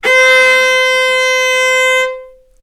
vc-C5-ff.AIF